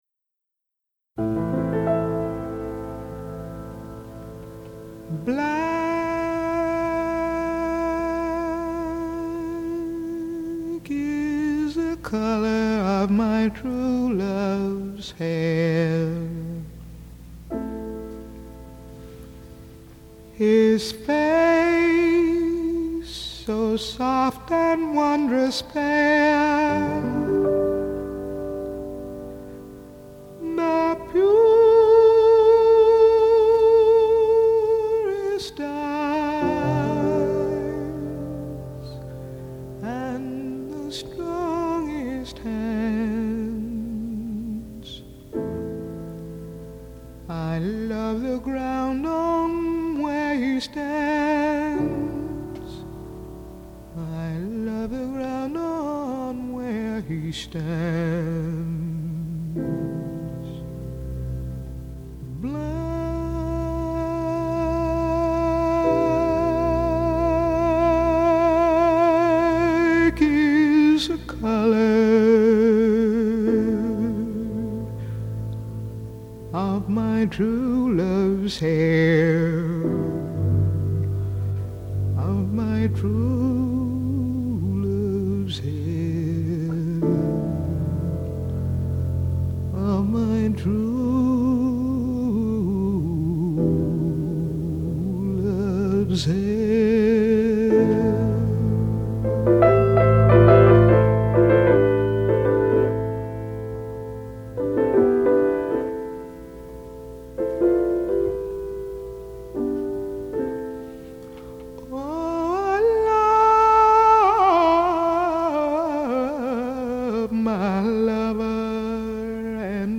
★ 八位爵士天后與六位爵士天王傳世名曲！